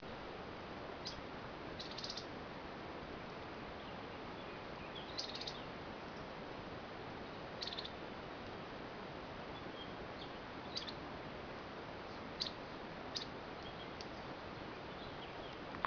c'è un uccellino nascosto tra cespugli ed arbusti, non si fa quasi vedere ma si fa sentire con questi 'tocchi' anche a poca distanza.
Siamo in Toscana, agosto 2012 a 300 m.
Scricciolo ?